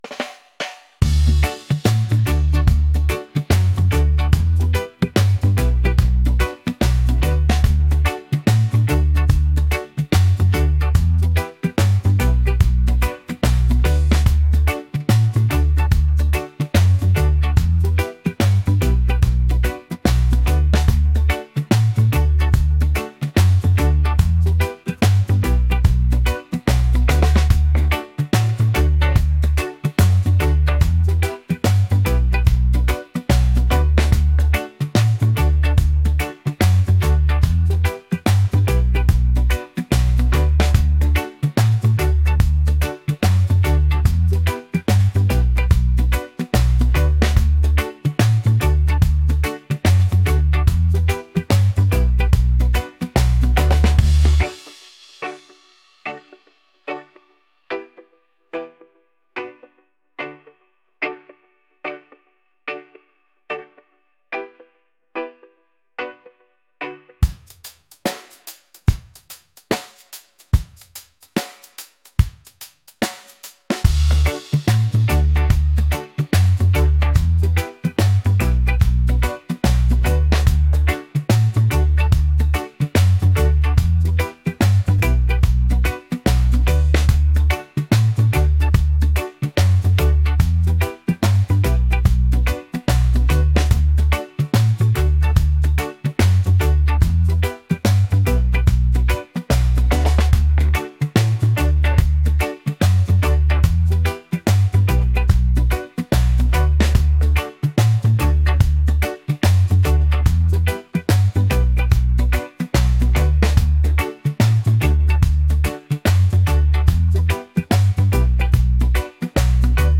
reggae | positive | upbeat